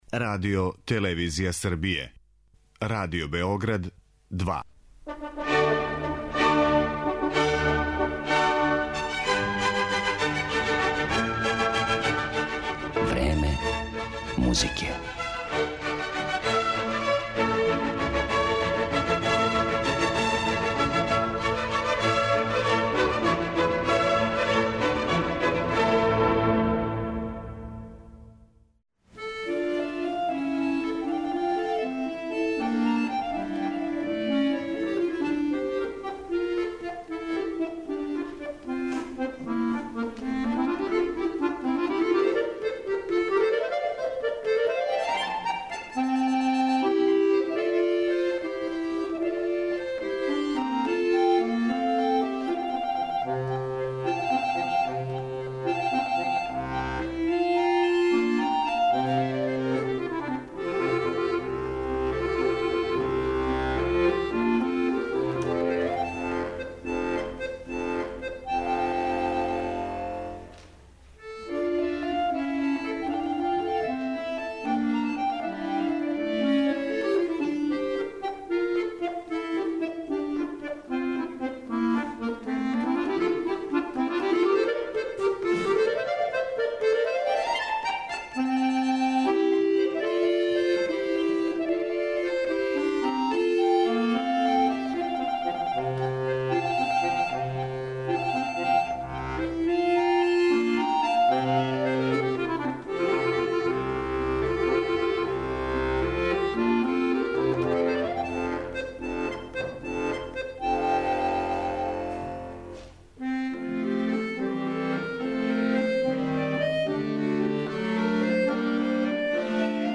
Данашња емисија је посвећена белоруском музичару Александеру Севастијану, врхунском виртуозу на хармоници.